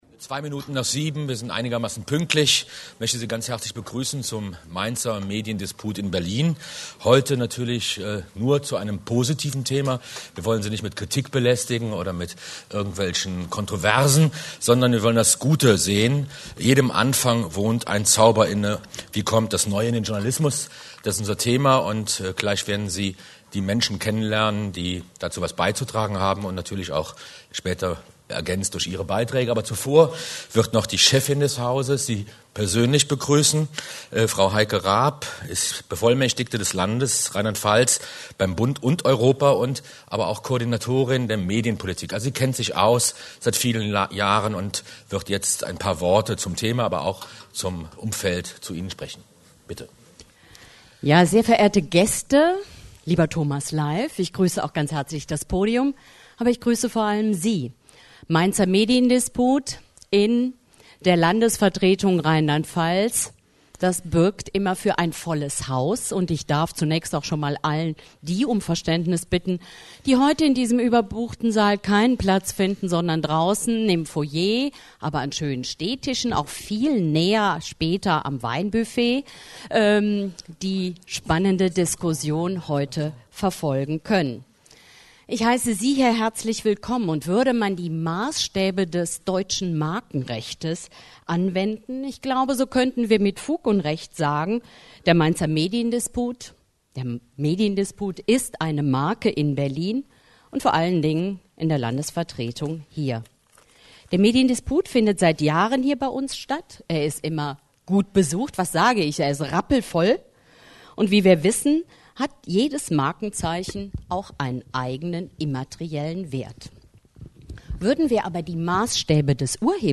MainzerMedienDisput – Diskussion (ab 09:11)
Landesvertretung Rheinland-Pfalz beim Bund, In den Ministergärten 6, 10117 Berlin